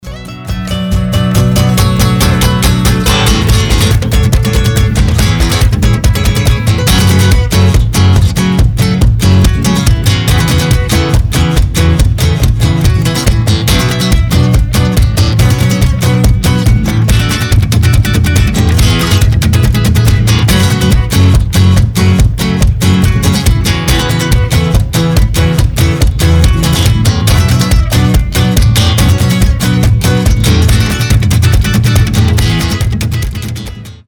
• Качество: 320, Stereo
гитара
мелодичные
заводные
без слов
latin rock
Acoustic
инструментальный рок